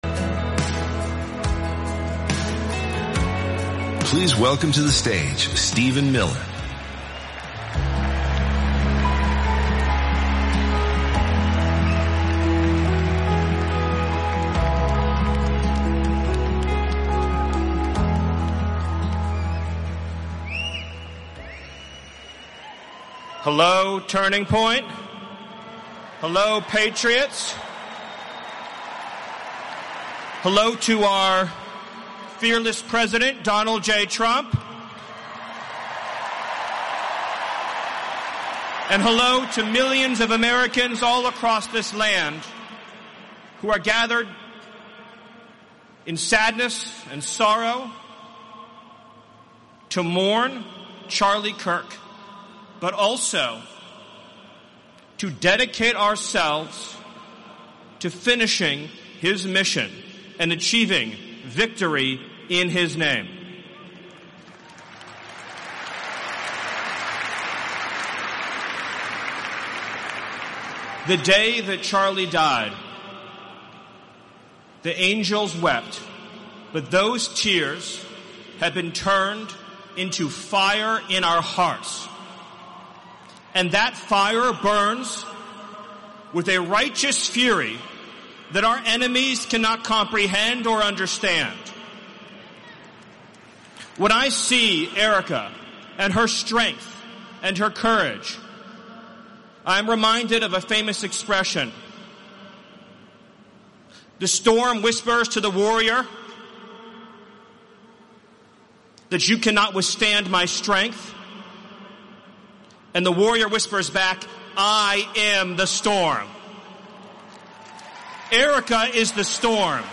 Stephen Miller, stellvertretender Stabschef des Weissen Hauses, hielt am 21. September 2025 im State Farm Stadium in Glendale, Arizona, eine Rede bei der Gedenkfeier für Charlie Kirk.